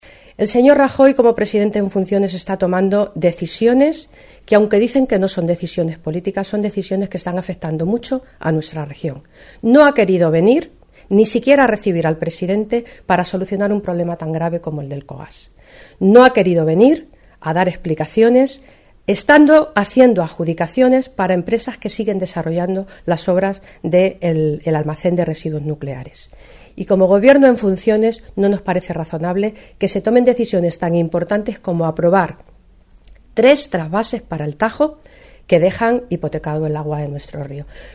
La diputada nacional del PSOE, Guadalupe Martín, asegura que "por muchos actos de partido que hagan no van a poder parar la grave crisis interna que atenaza a los 'populares'"
Cortes de audio de la rueda de prensa